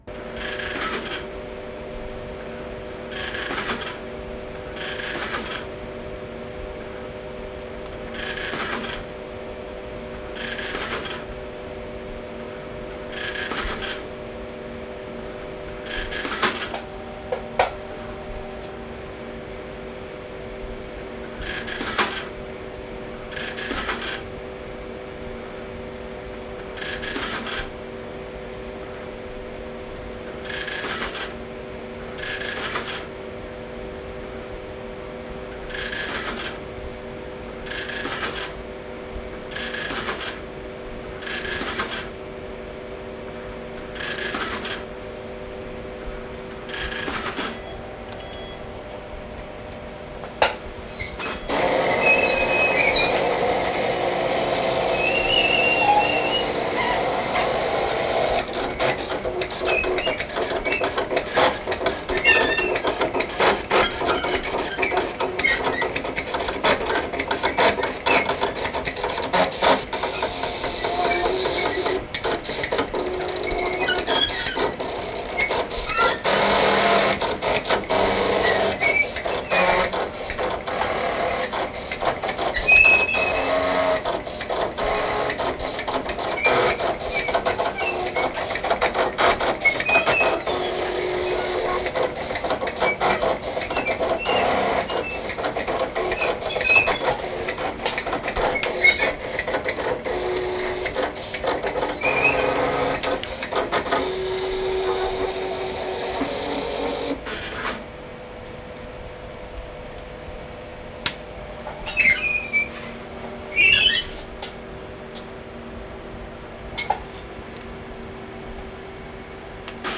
(2)コンピューターとイスのデュオ(14.4MB)